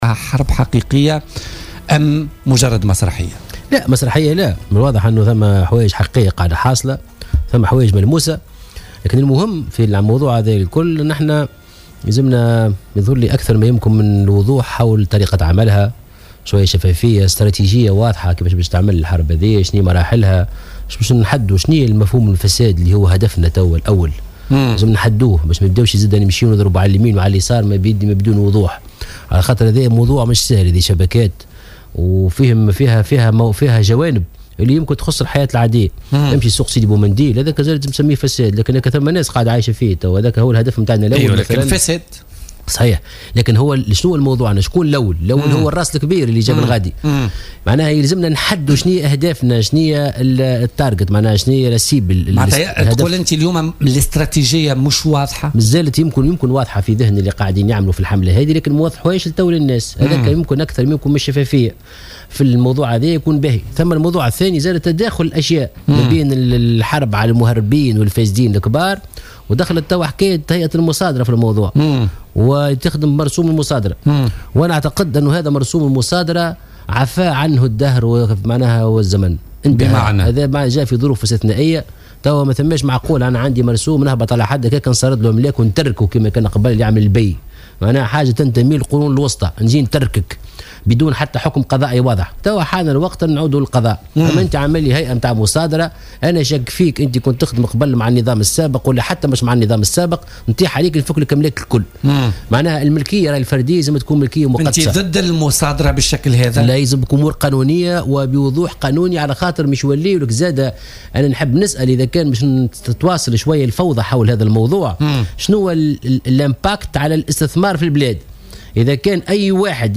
وقال ضيف "بوليتيكا" بـ"الجوهرة اف أم"، إن المرسوم المتعلق بالمصادرة تجاوزه الزمن ولا يمكن تطبيقه بشكله الحالي، مشددا على ضرورة انتهاج اجراءات قضائية تفاديا لأي لبس بالإضافة إلى آثاره السلبية على الاستثمار.